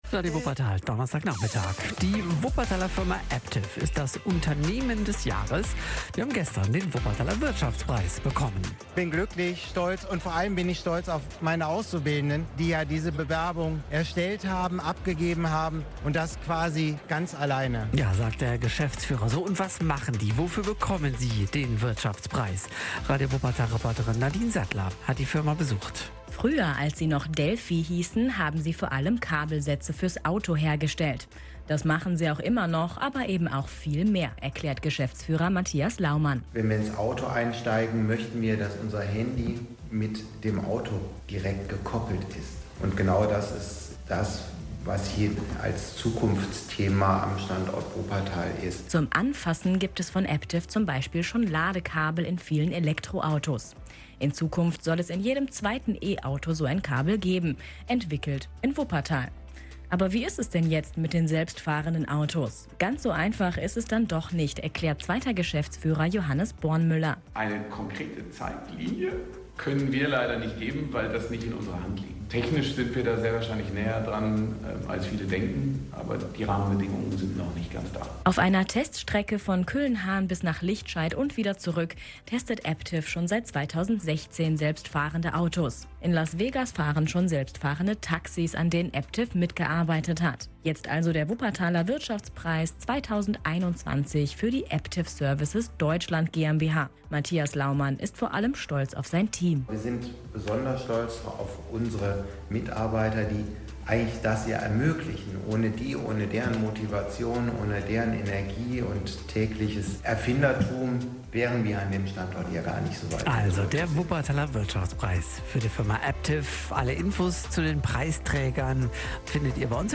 Am 27. Oktober 2021 wurden in der Glashalle der Sparkasse die Wuppertaler Wirtschaftspreise 2021 verliehen.
wuppertaler_wirtschaftspreis_mitschnitt_aptiv-unternehmen-des-jahres-ii.mp3